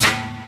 Waka SNARE ROLL PATTERN (87).wav